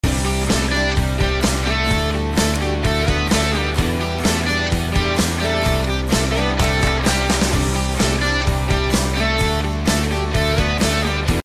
bubble Machine